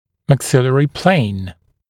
[mæk’sɪlərɪ pleɪn][мэк’силэри плэйн]плоскость верхней челюсти, верхнечелюстная горизонталь